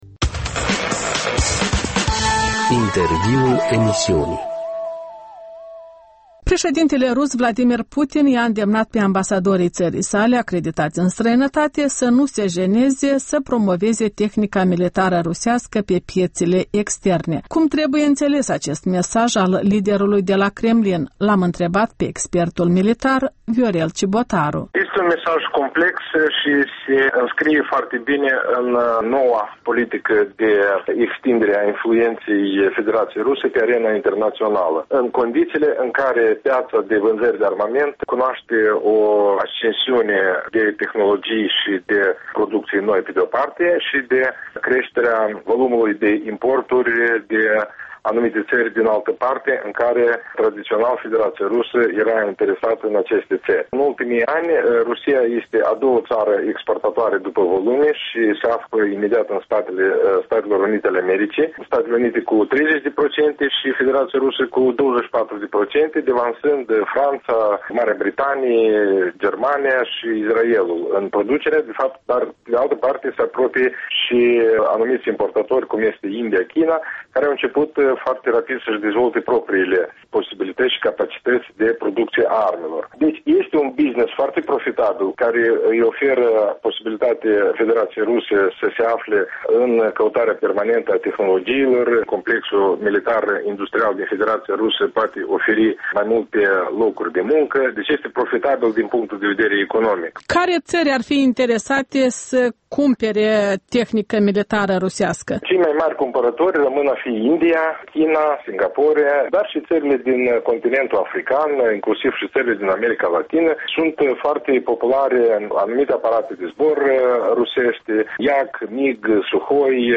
Interviurile Europei Libere: Vînzările de armament al Rusiei comentate de Viorel Cibotaru